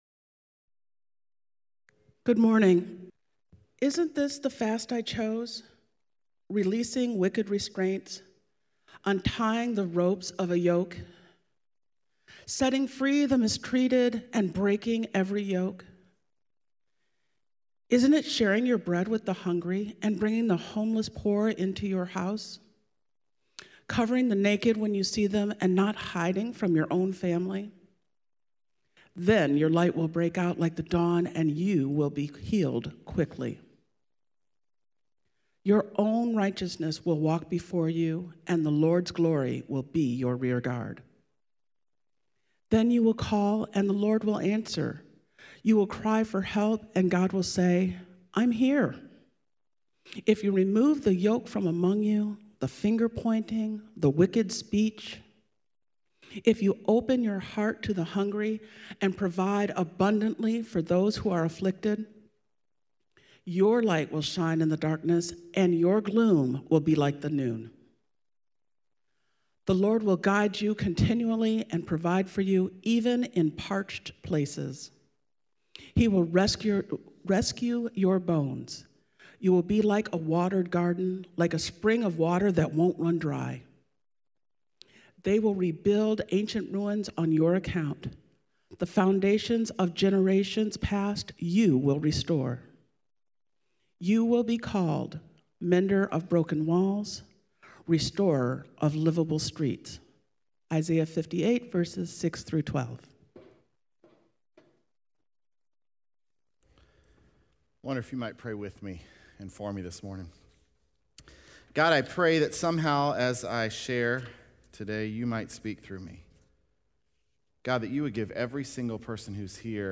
Sunday morning Message The Struggle Is Real
Sermons